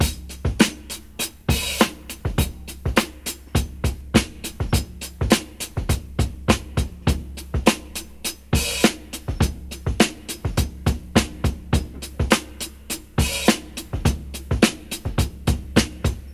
• 103 Bpm Drum Loop C# Key.wav
Free drum loop sample - kick tuned to the C# note. Loudest frequency: 2419Hz
103-bpm-drum-loop-c-sharp-key-ZI0.wav